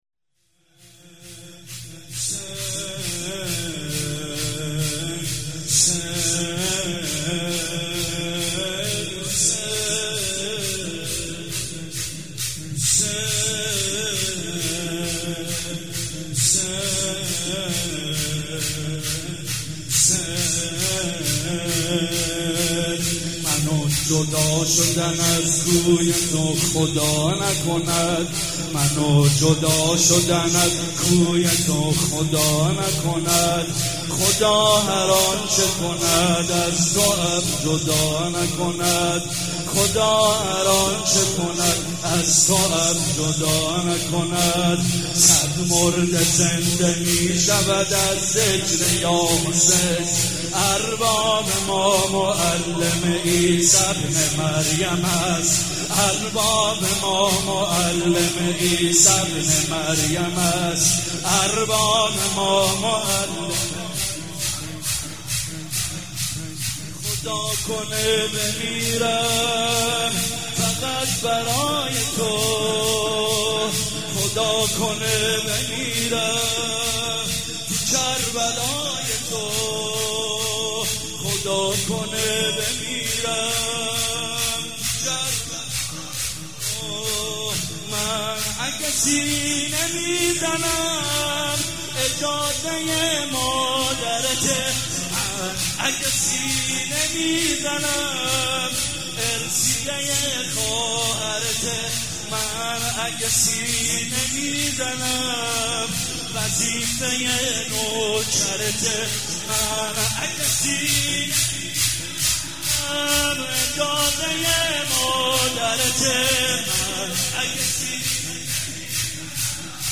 شب دوم فاطميه دوم١٣٩٤
شور
مداح
مراسم عزاداری شب دوم